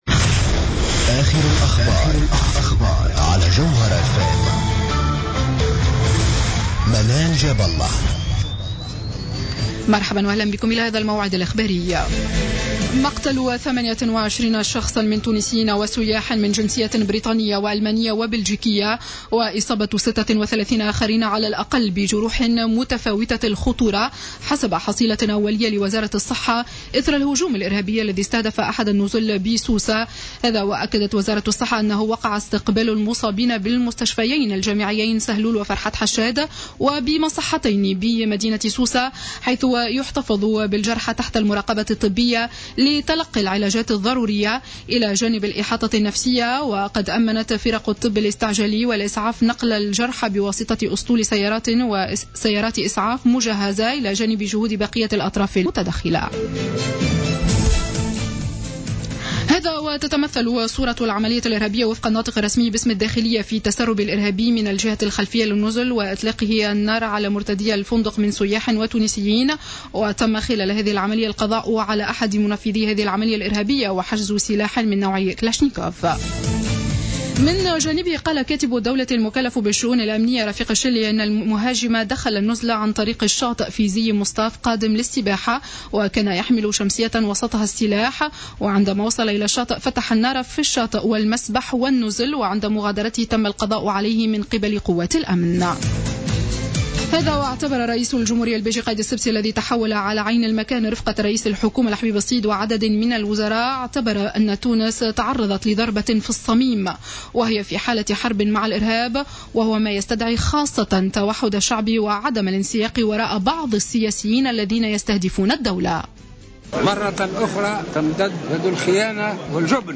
نشرة أخبار الخامسة مساء يوم الجمعة 26 جوان 2015